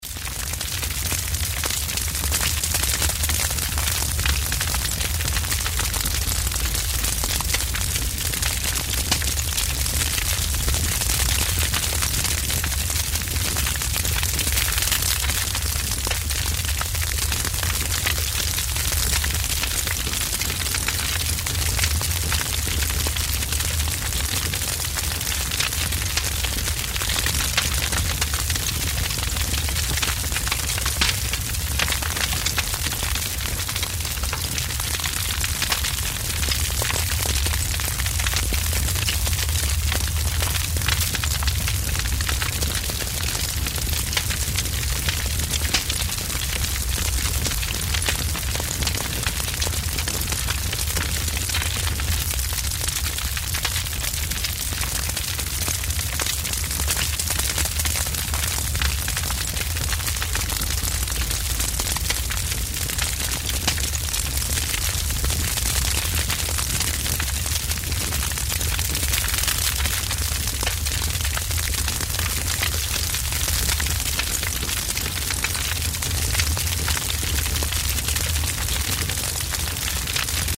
Звуки факела
Атмосферный шум пламени